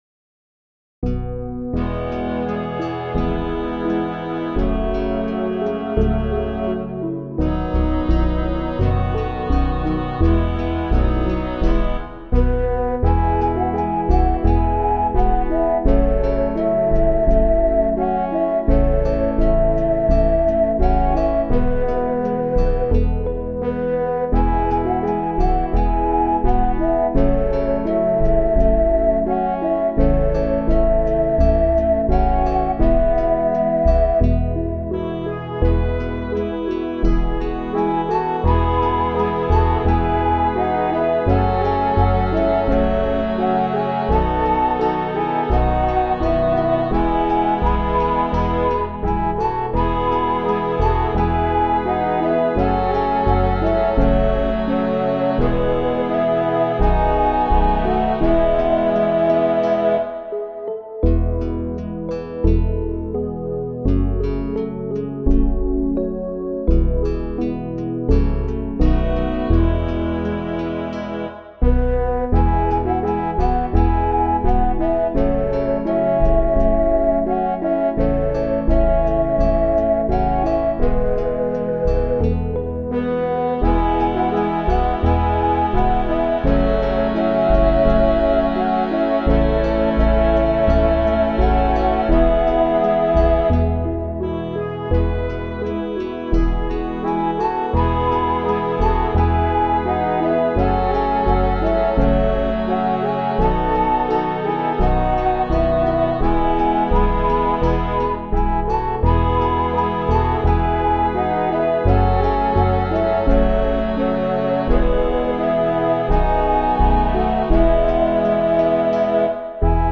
MP3 instrumental